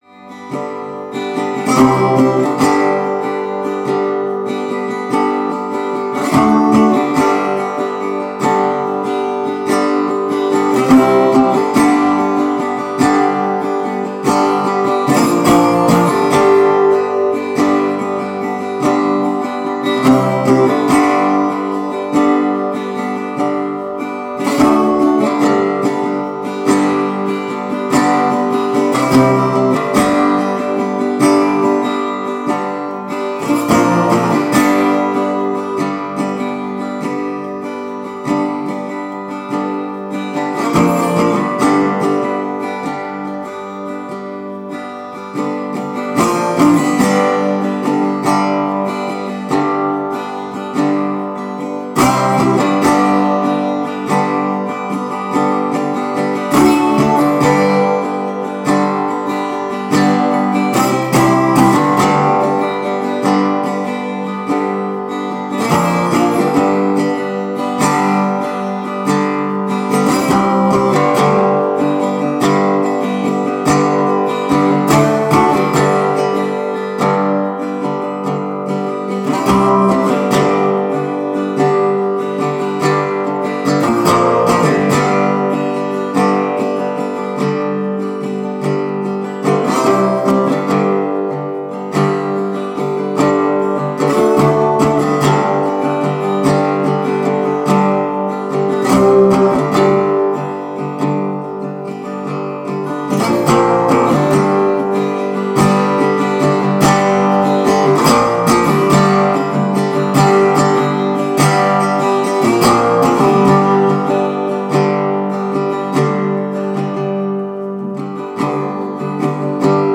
Dobro Resonator Guitar.